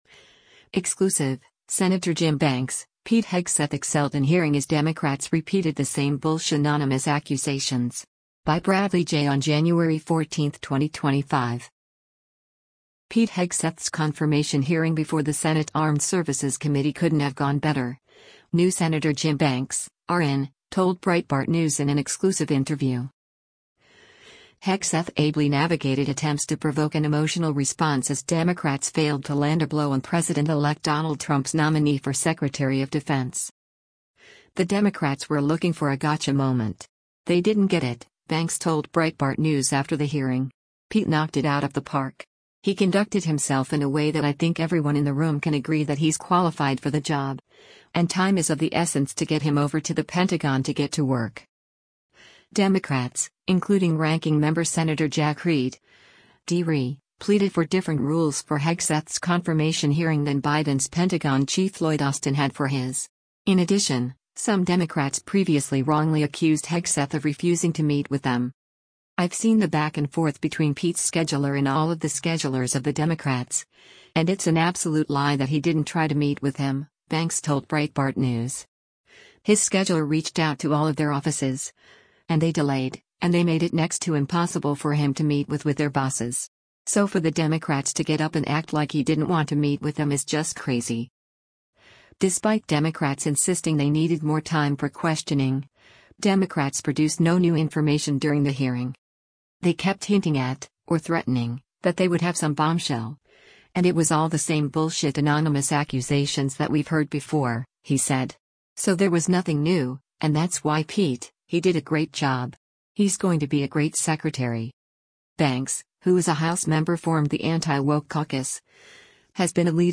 Pete Hegseth’s confirmation hearing before the Senate Armed Services Committee “couldn’t have gone better,” new Senator Jim Banks (R-IN) told Breitbart News in an exclusive interview.